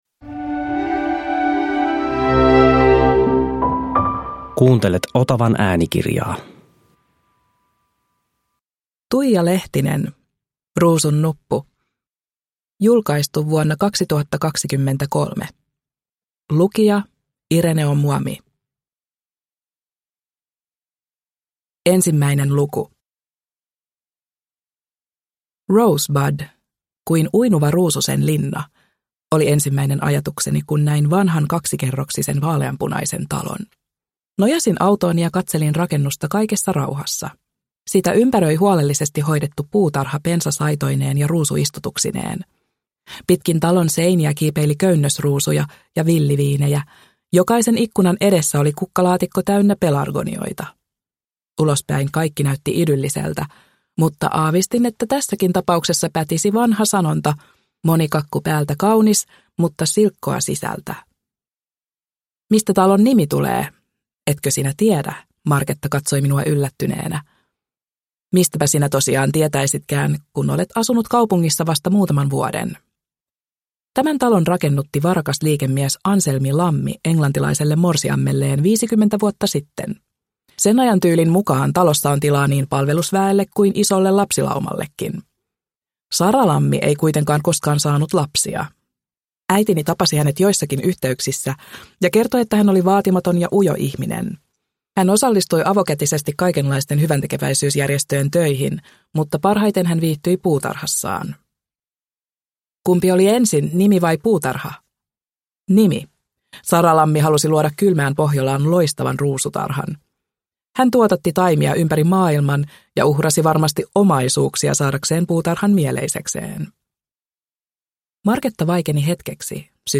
Ruusunnuppu – Ljudbok – Laddas ner